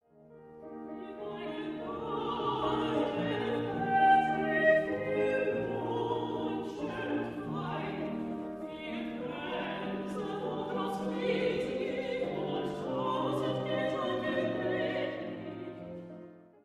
Dúo Femenino (audio/mpeg)
Dúo femenino